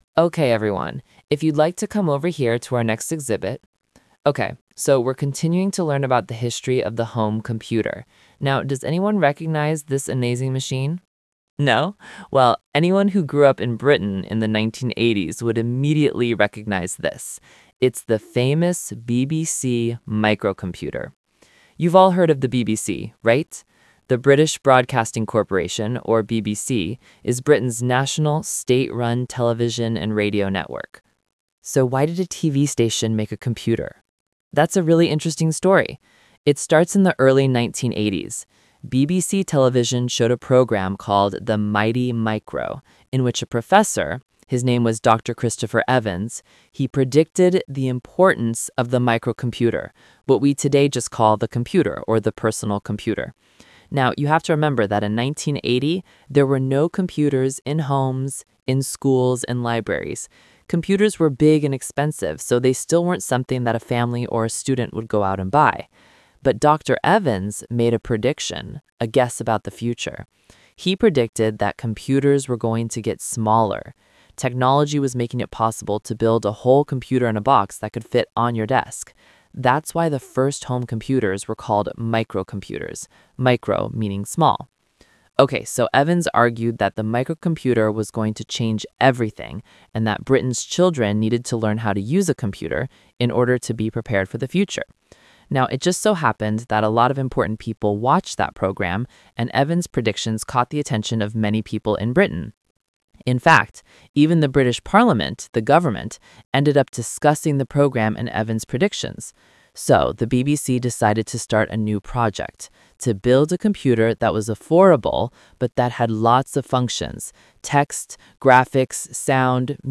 Talk/Lecture 3: Listen to a tour guide talking to some visitors at a museum about the BBC computer.